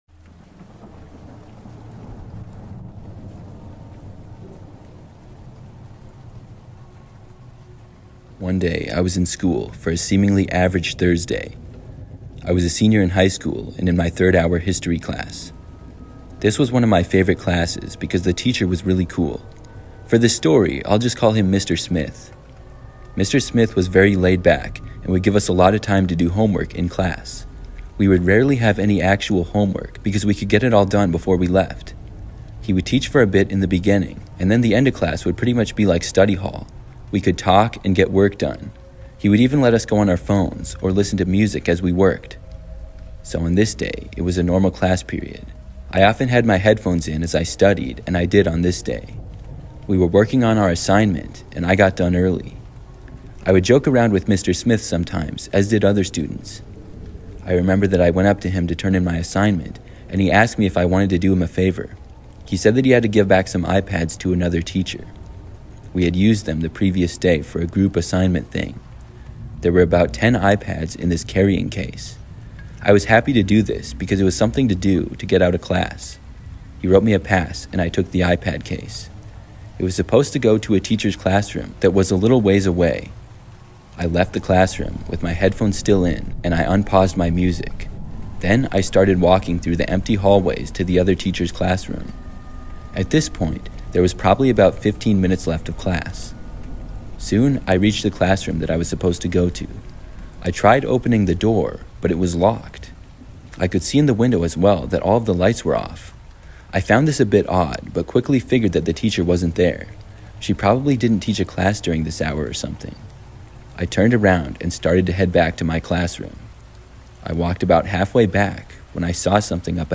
True Pizza Delivery Horror Stories (With Rain Sounds) That Will Change How You See Deliveries